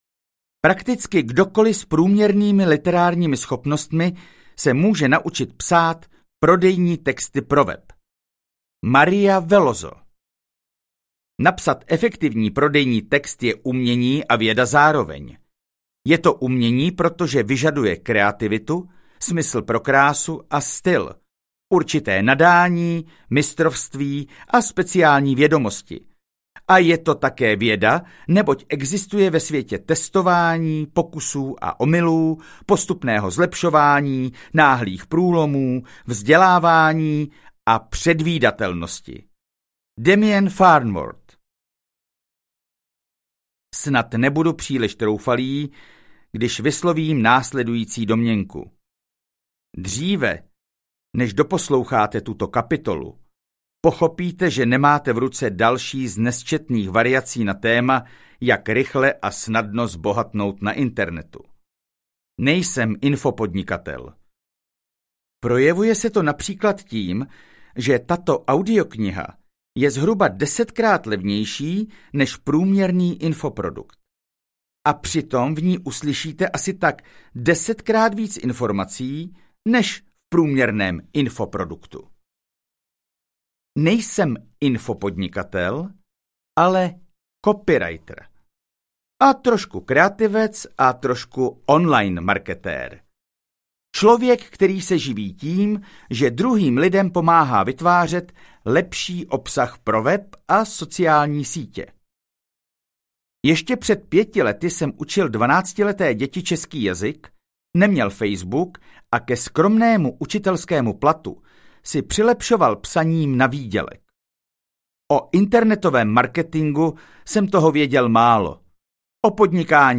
Ukázka z knihy
webcopywriting-pro-samouky-audiokniha